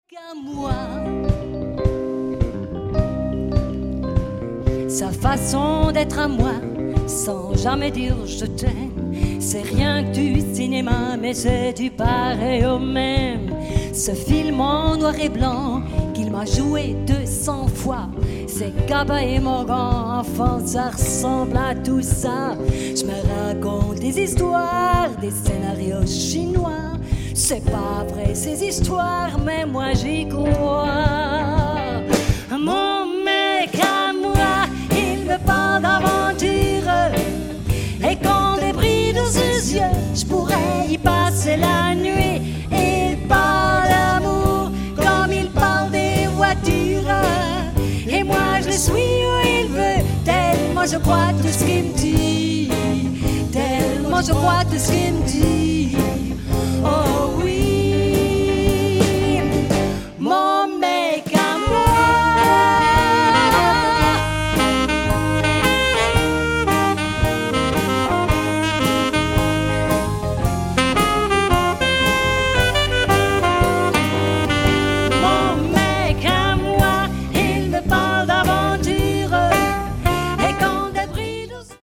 Jubiläumskonzert